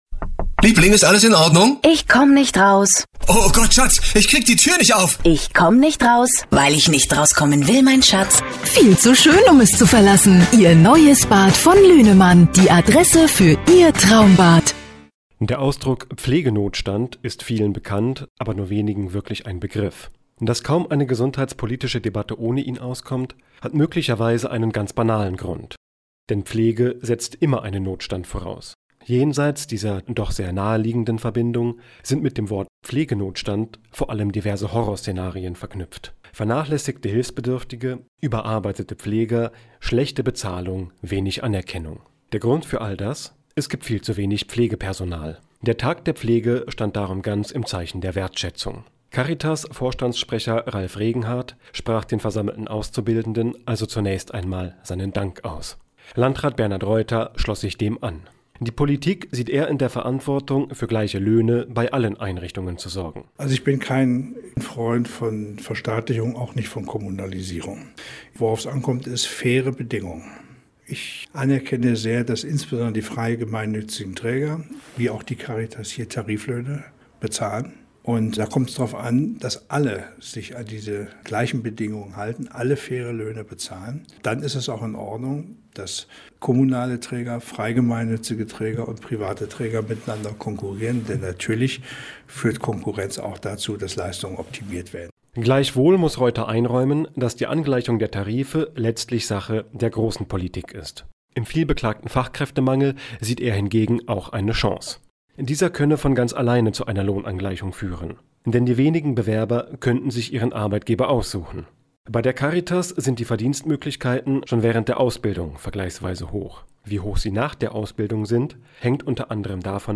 Aus diesem Anlass lud die Caritas Duderstadt bereits vorab zu einem sogenannten Azubi-Frühstück.